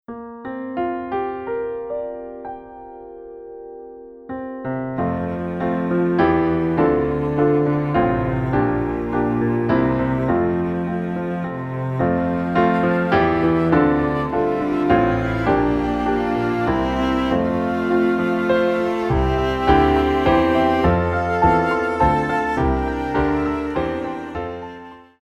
Entdecken - Erzählen - Begreifen: Spiellieder mit CD.